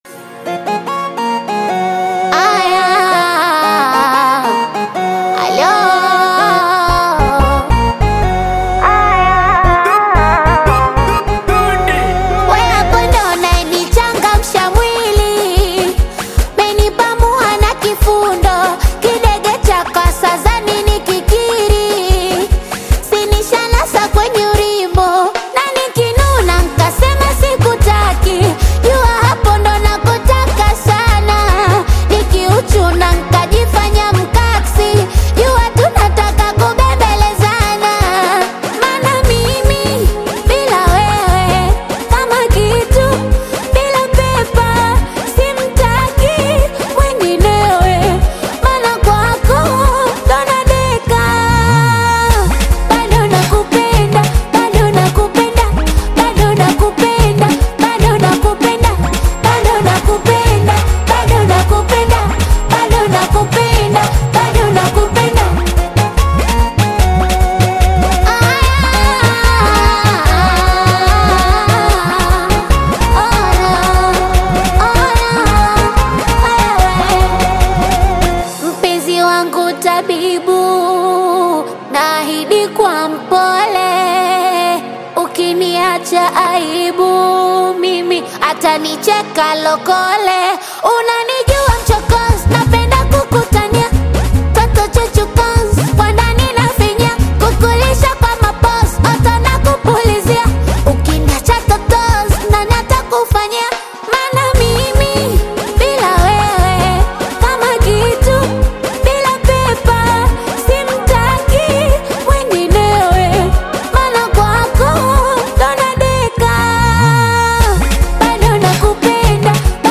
heartfelt Afro-Pop single
Genre: Singeli